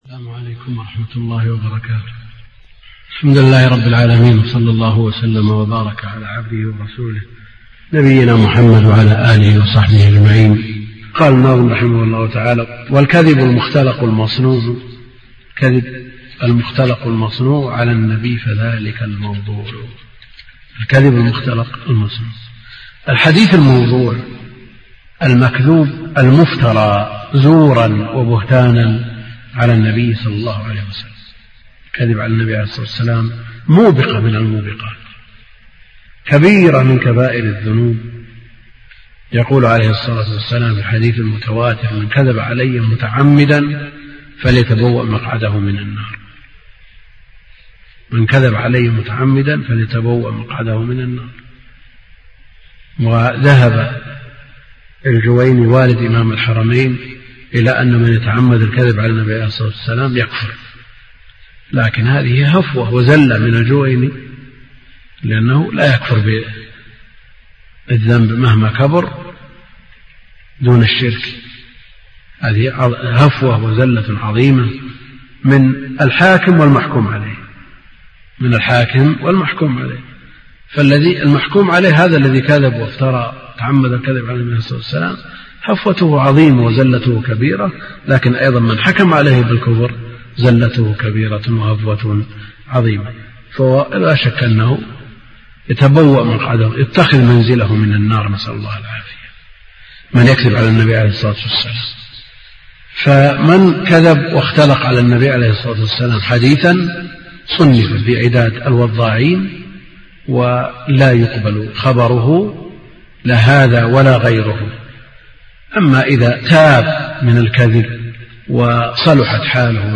أرشيف الإسلام - ~ أرشيف صوتي لدروس وخطب ومحاضرات الشيخ عبد الكريم الخضير